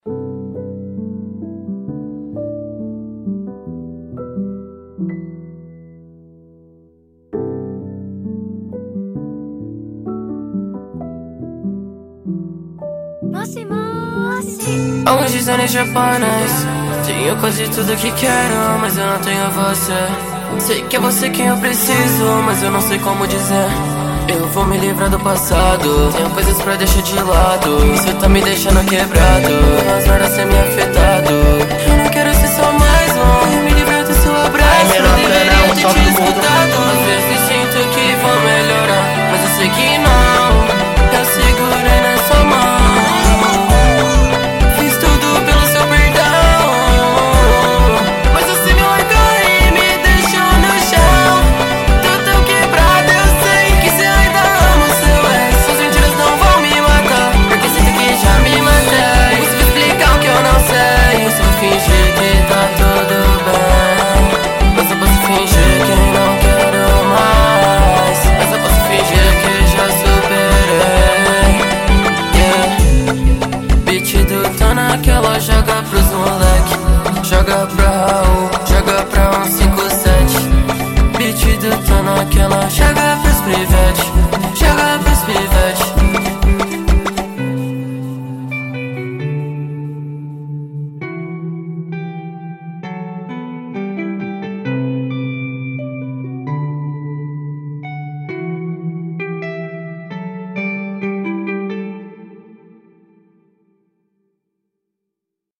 2024-09-27 08:52:36 Gênero: Funk Views